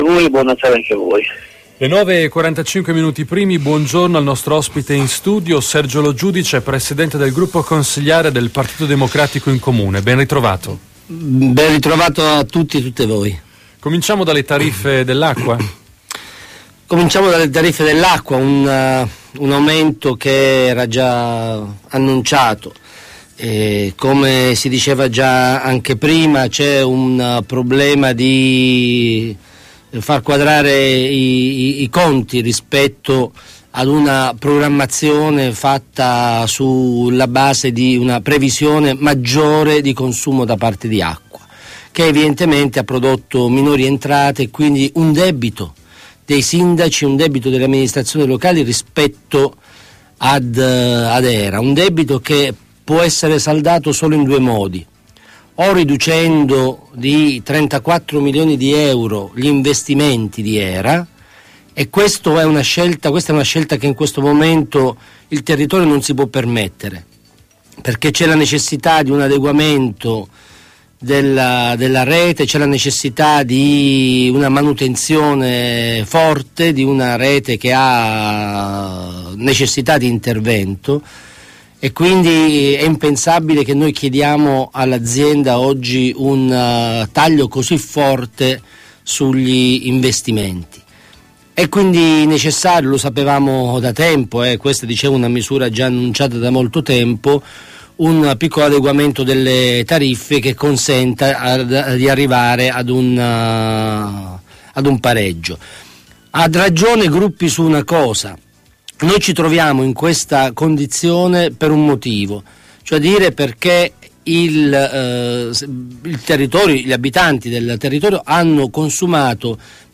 Intervista Radio Tau del capogruppo PD Sergio Lo Giudice il 21dicembre